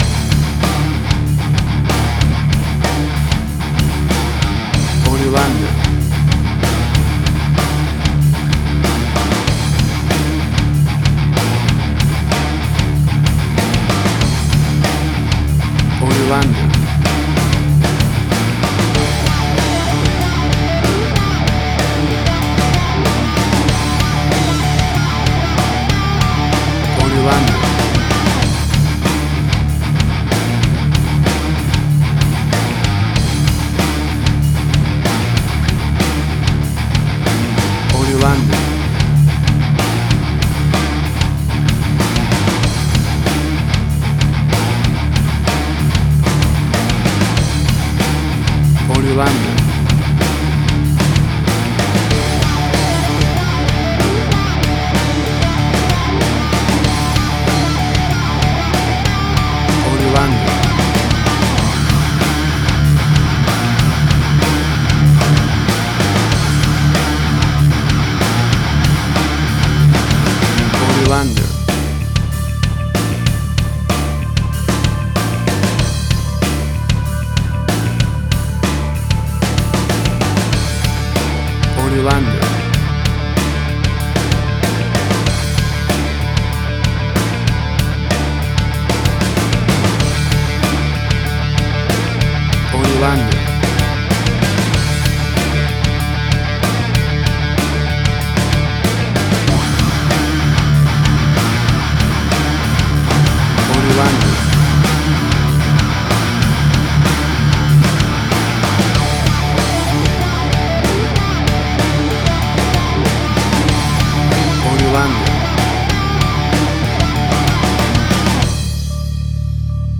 Hard Rock, Similar Black Sabbath, AC-DC, Heavy Metal.
Tempo (BPM): 100